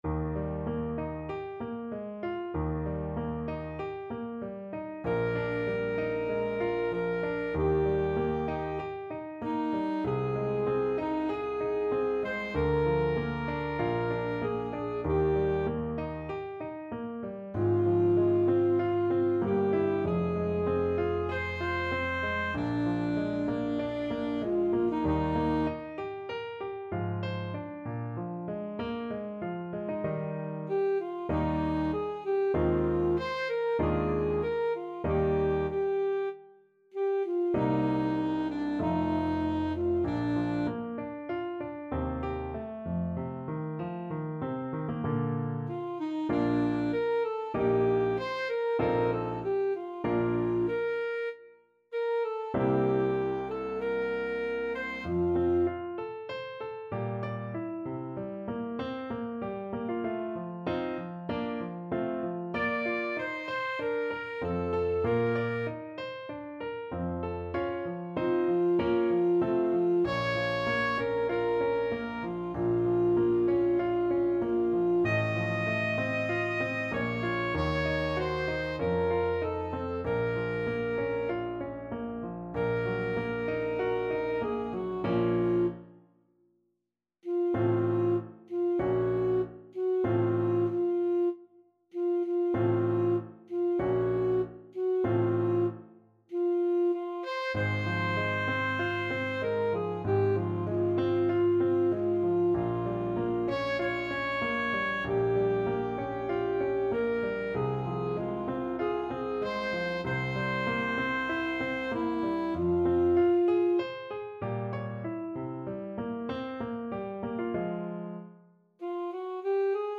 Classical Mozart, Wolfgang Amadeus Abendempfindung an Laura, K.523 Alto Saxophone version
Alto Saxophone
Eb major (Sounding Pitch) C major (Alto Saxophone in Eb) (View more Eb major Music for Saxophone )
D5-Eb6
~ = 96 Andante
4/4 (View more 4/4 Music)
Classical (View more Classical Saxophone Music)